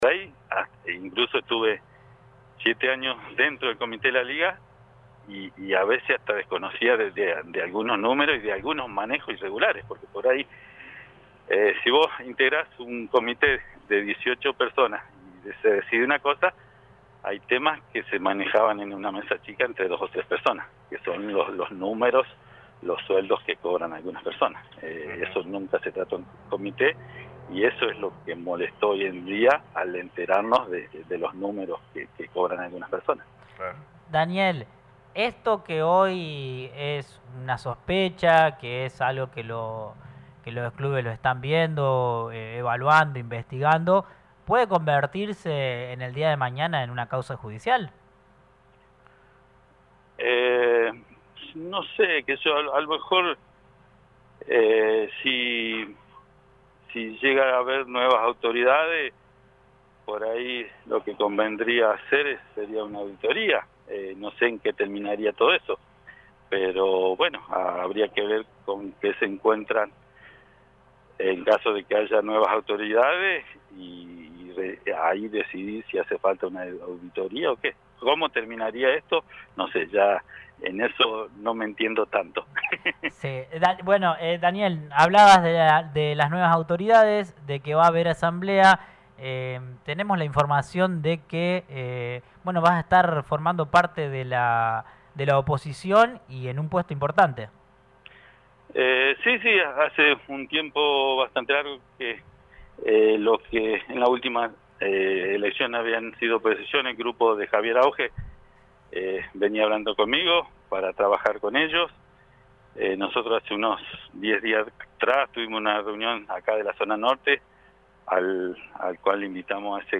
en dialogo con LA RADIO 102.9.